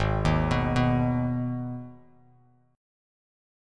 Listen to Abm strummed